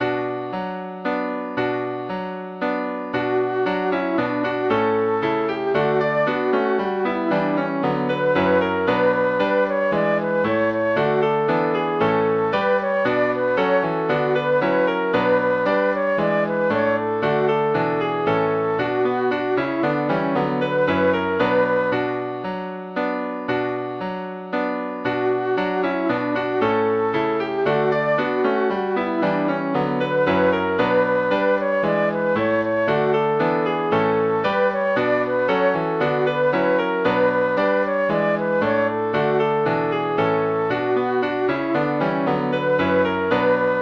Midi File, Lyrics and Information to Dance to your Daddy?
daddy.mid.ogg